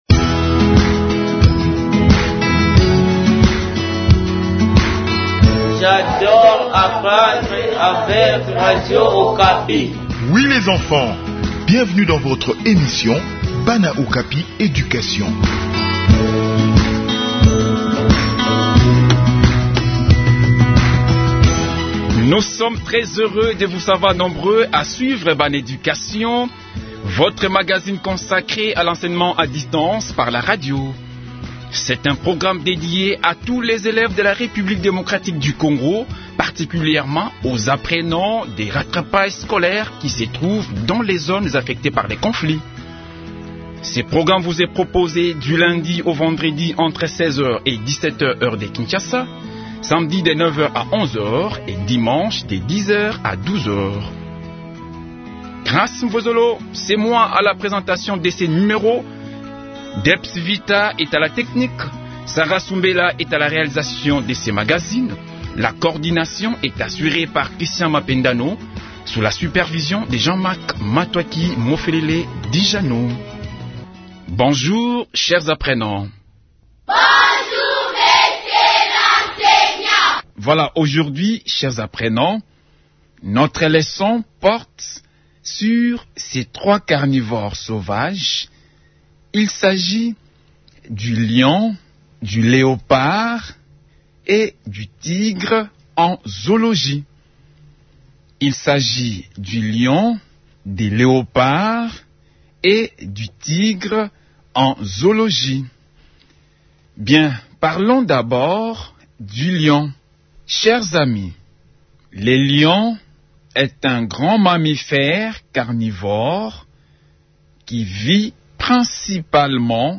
Cette leçon des sciences enrichit la culture générale et le savoir zoologique.